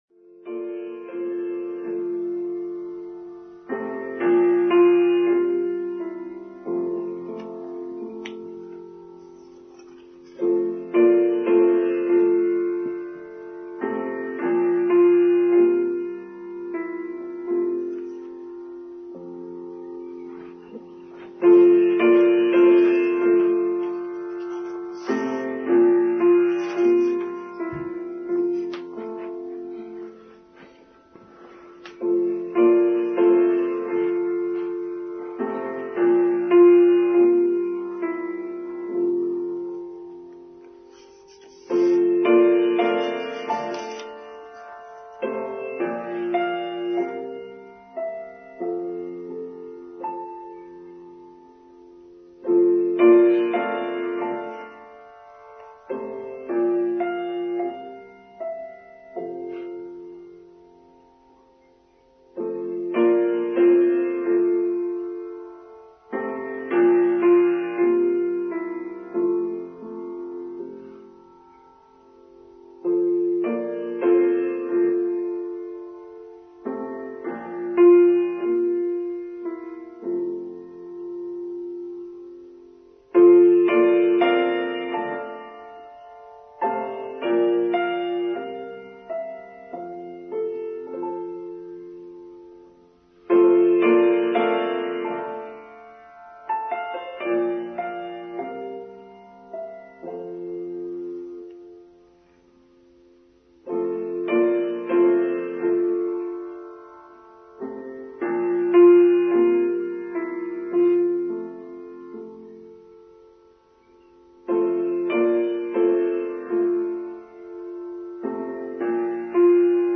Beltane: Online Service for Sunday 2nd May 2021
beltanejoinedtrimmed.mp3